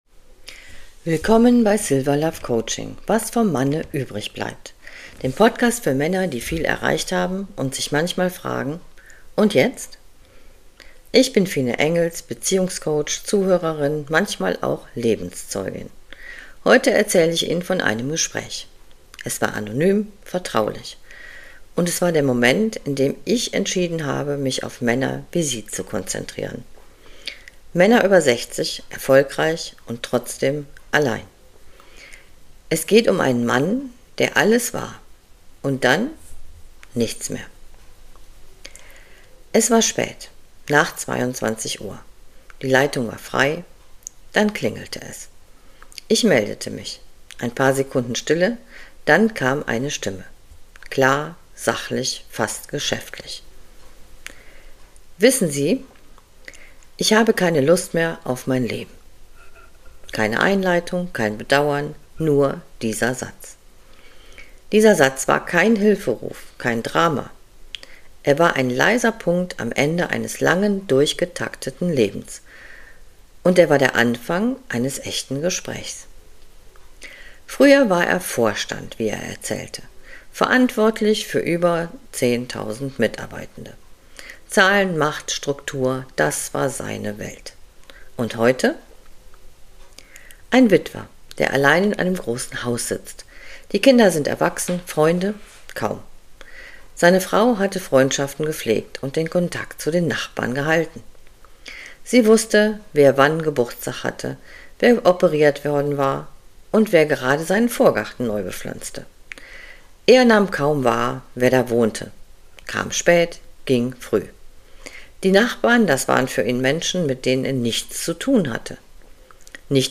Ein Gespräch über Identitätsverlust, Schuld und den Mut zur späten Veränderung.